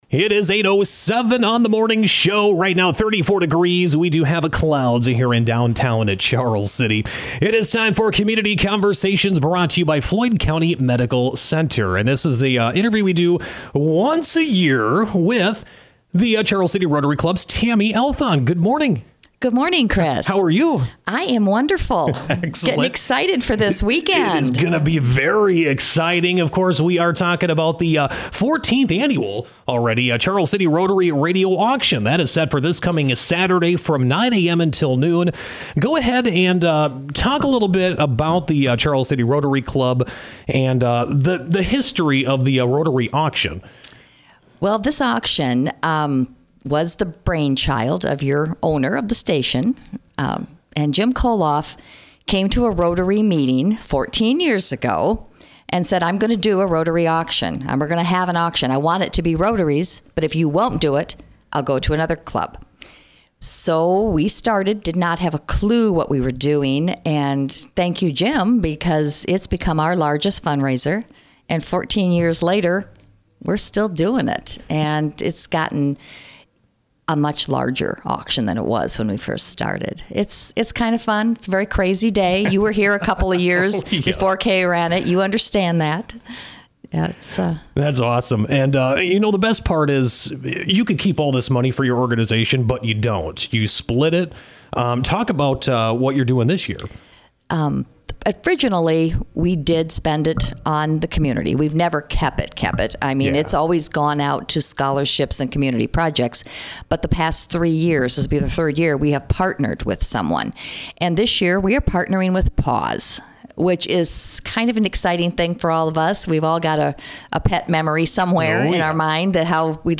Community Conversations are sponsored by the Floyd County Medical Center